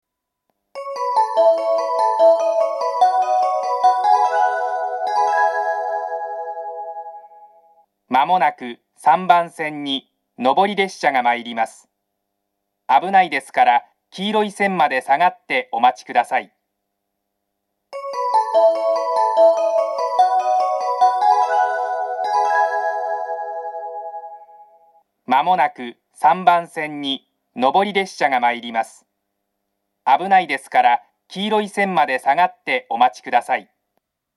３番線接近放送 上り本線です。